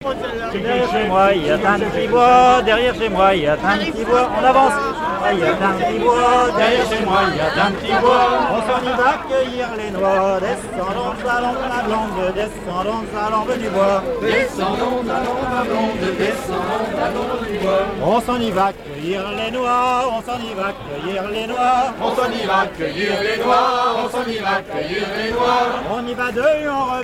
Île-d'Yeu (L')
danse : passepied
Genre laisse
chansons à danser
Pièce musicale inédite